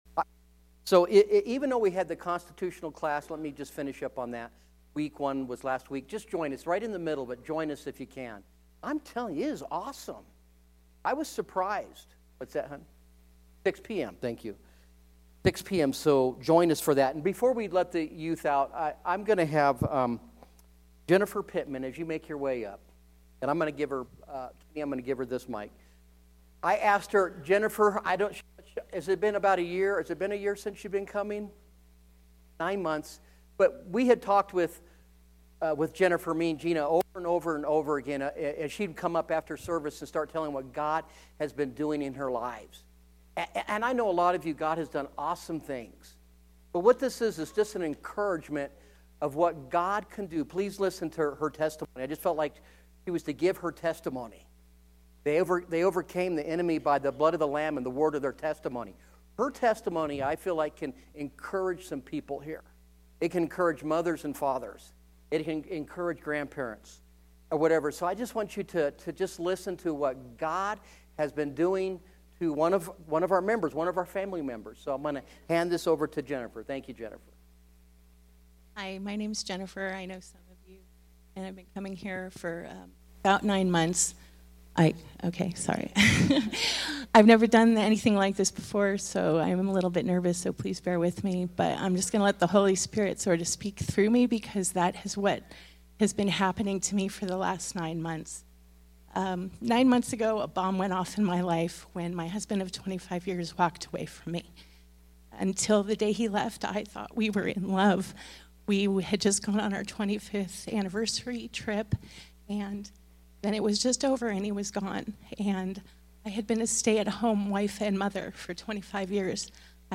SERMONS – 2022 | AZ Family Church